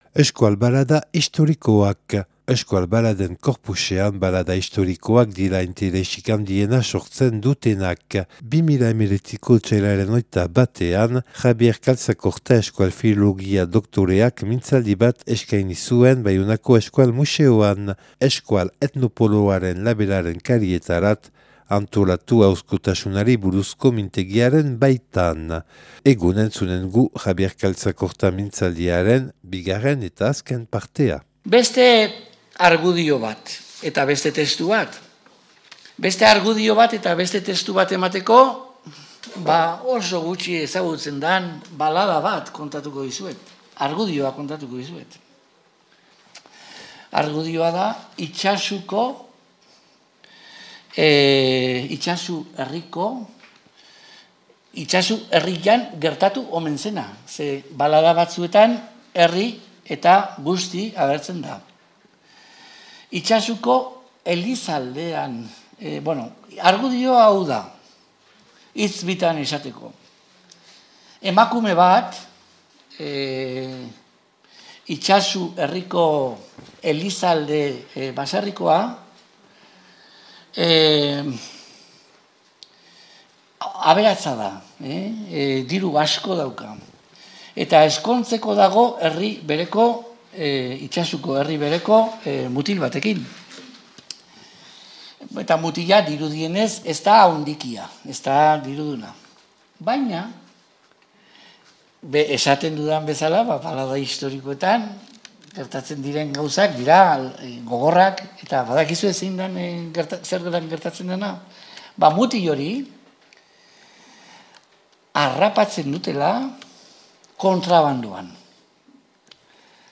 (Grabatua 2019. Otsailaren 21an Baionako Euskal Museoan, Euskal etnopoloaren mintzaldia).